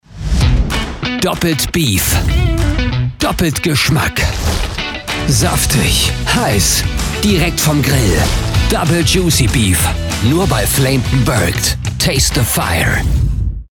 Commerciale, Cool, Mature, Amicale, Corporative
Commercial